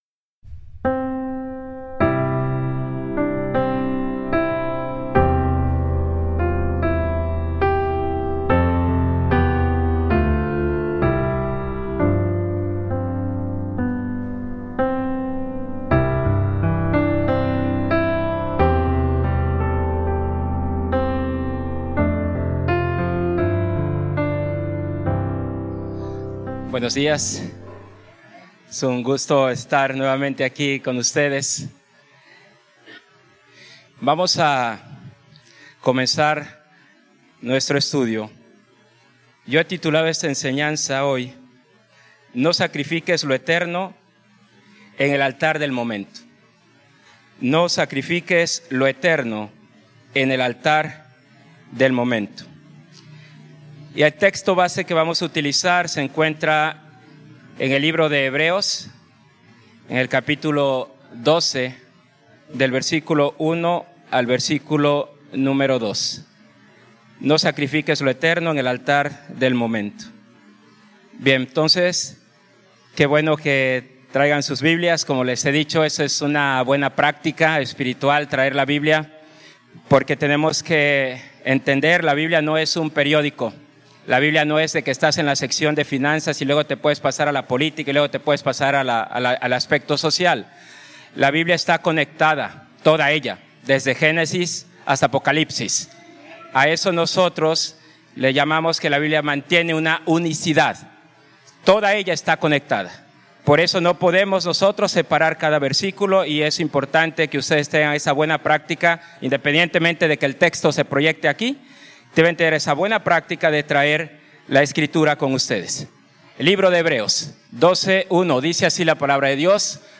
Predicación: No sacrifiques lo eterno en el altar del momento
Predicación textual basada en el pasaje de la epístola a los Hebreos 12:1–2